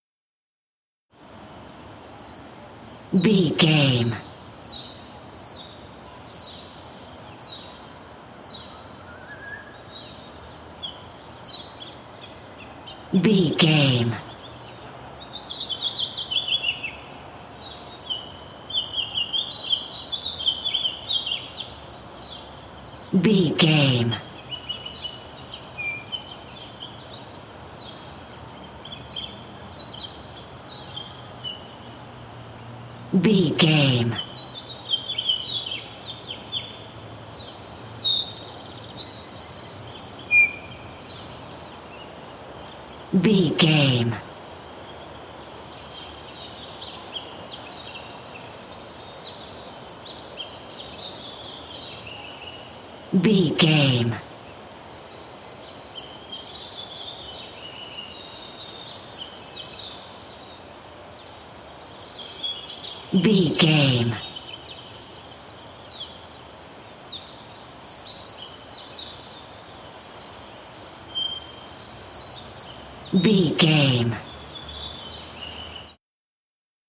City park birds
Sound Effects
nature
urban
ambience